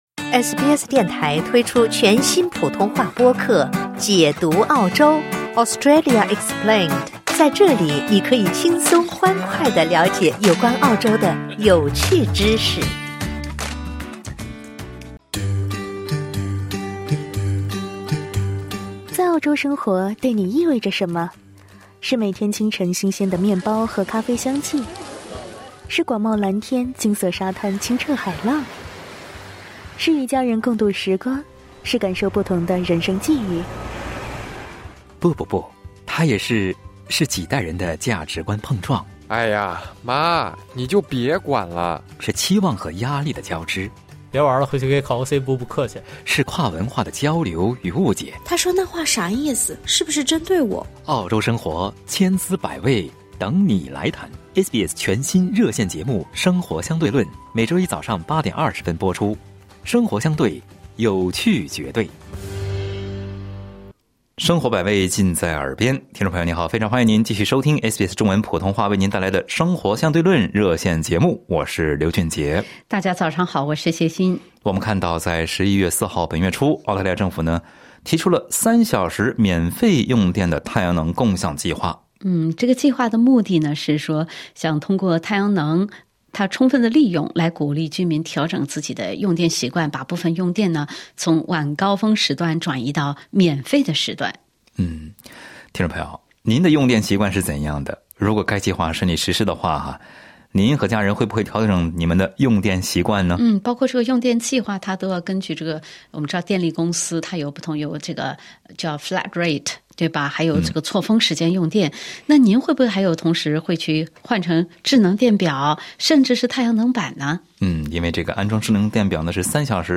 在本期《生活相对论》热线节目中，听友们就此发表了各自看法。